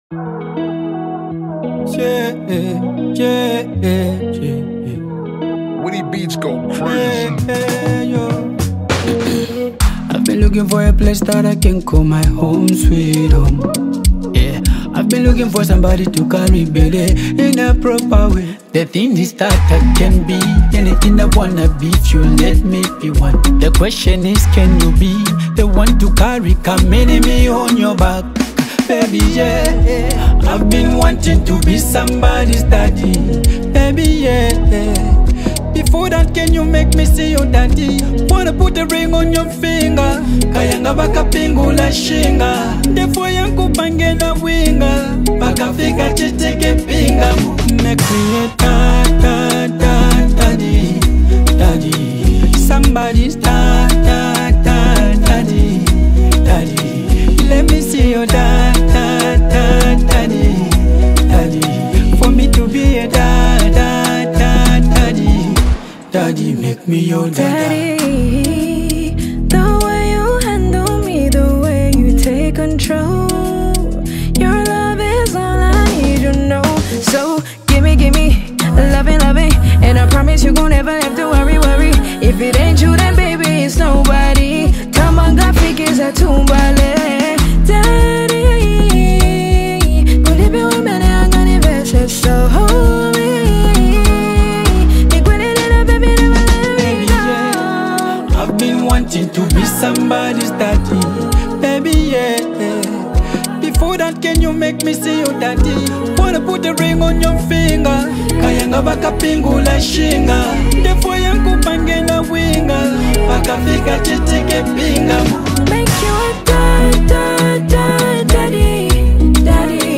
heartfelt and emotional ballad